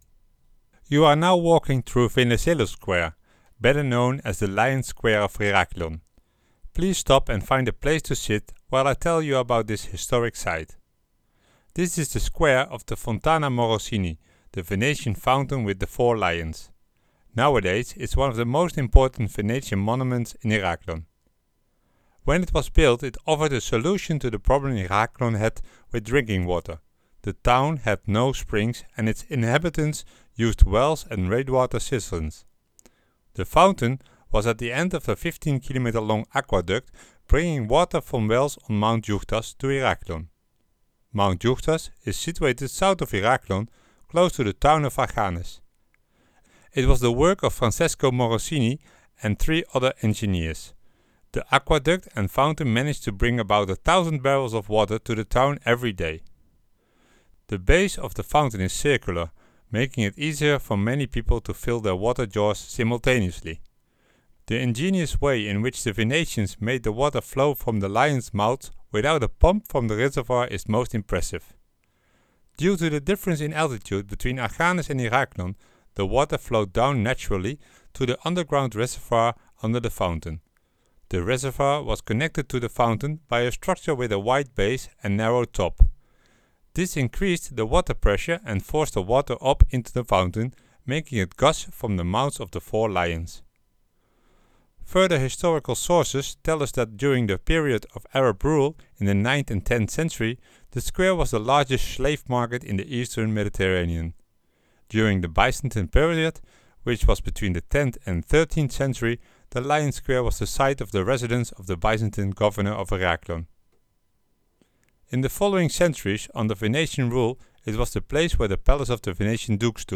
It’s like having a guide or hostess sitting next to you in the passenger seat!
The below mentioned RAGiF is automatically playing when you enter the main square in the city of Heraklion, during the city walk. It tells you about the fountain and its history.